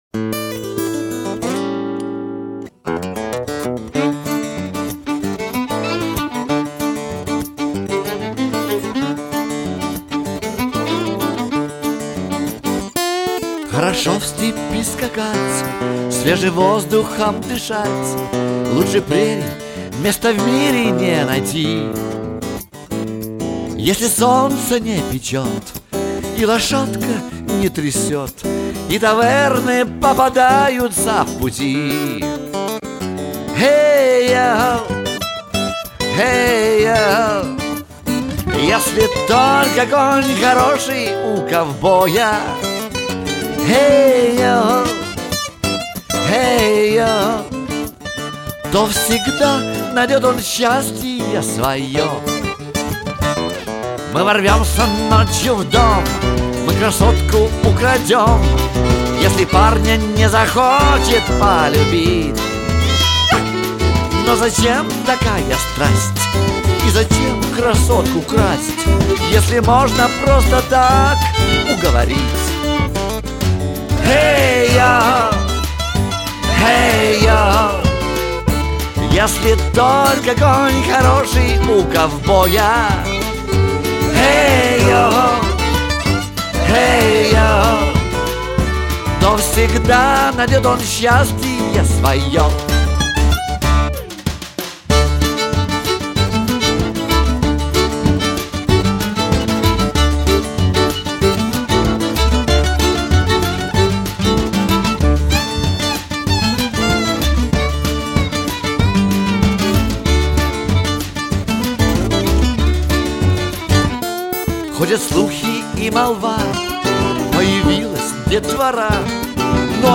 гитара, вокал
скрипка